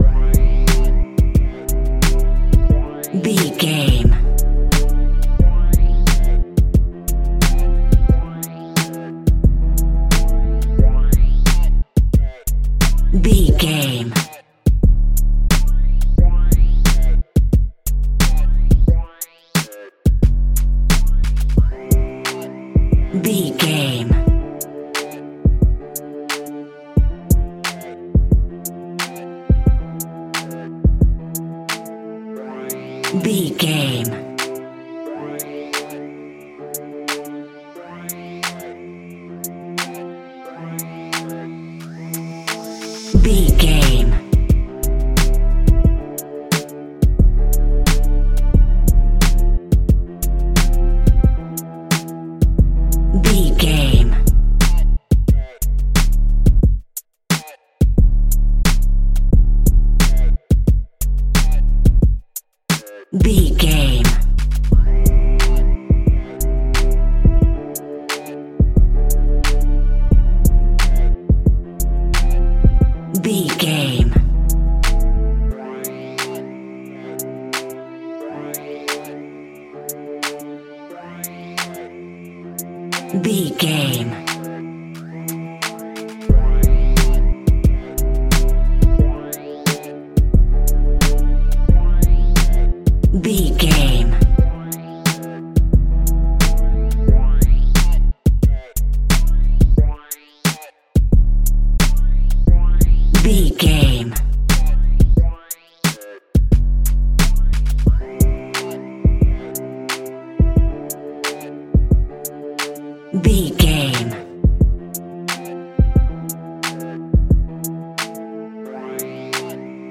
Ionian/Major
D
aggressive
intense
driving
bouncy
energetic
dark
drum machine
bass guitar
synthesiser